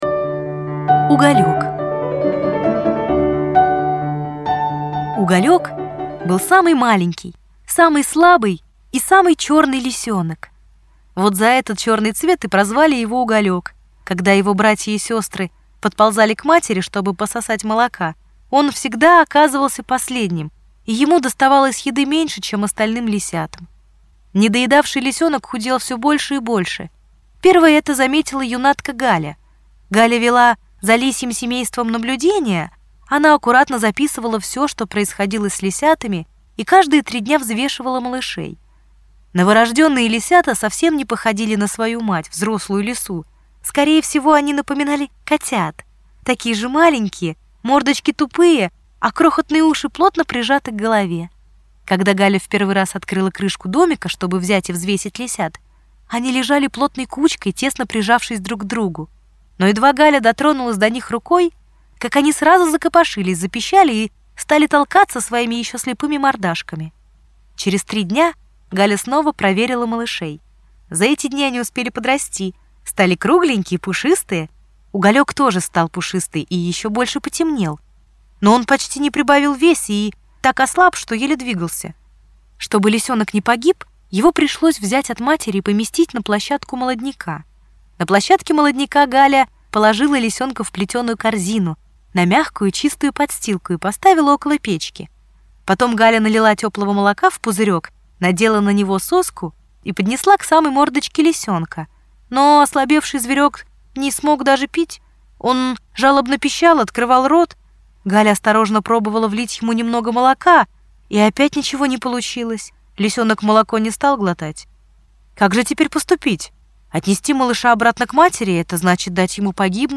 Аудиорассказ «Уголёк»
Очень красивый голос рассказчика